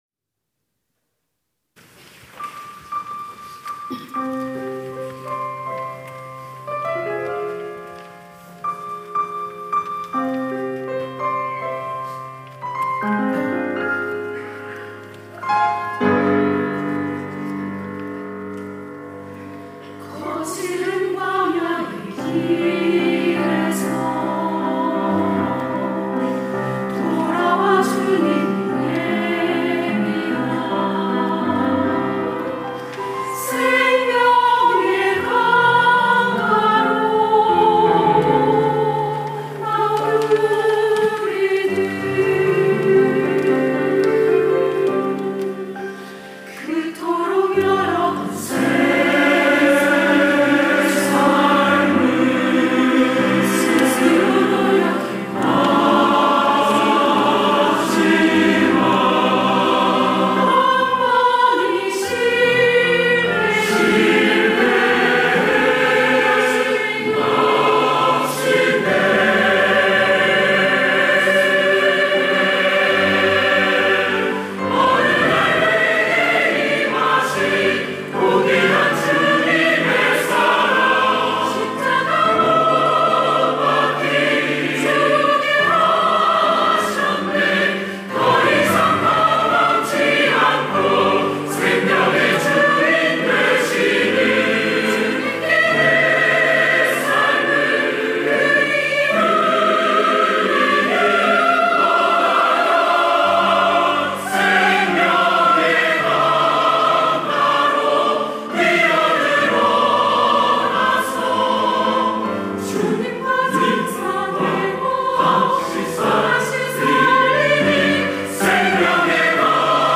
특송과 특주 - 생명의 강가로
이름 연합 중창단